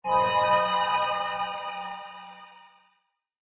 cave1.ogg